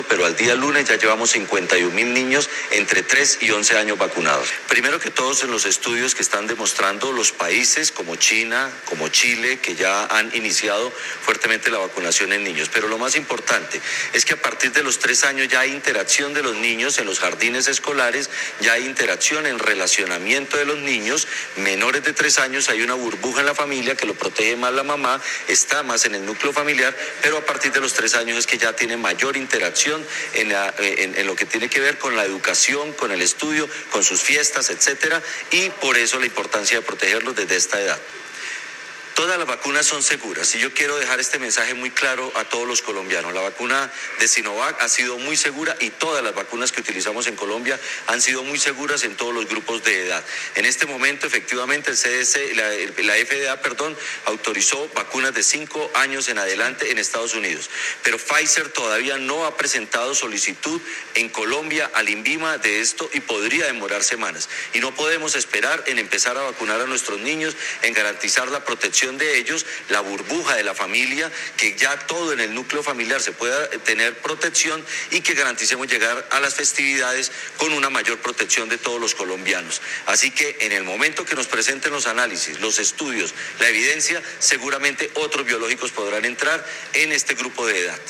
Audio de Gerson Bermont, director de Promoción y Prevención.